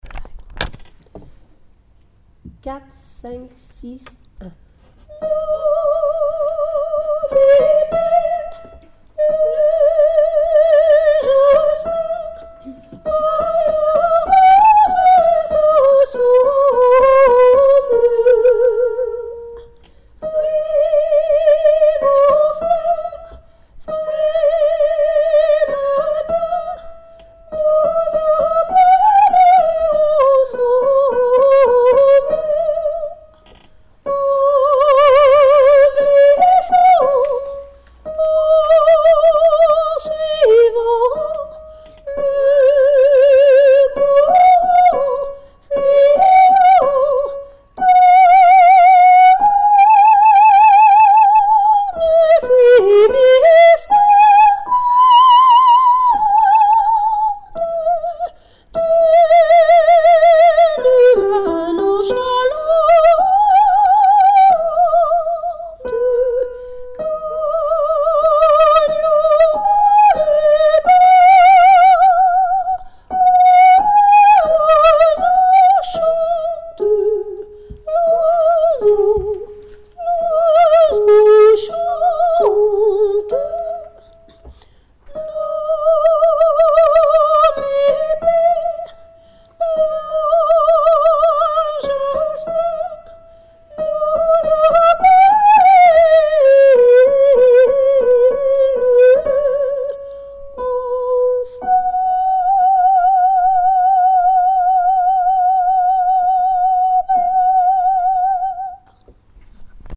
soprani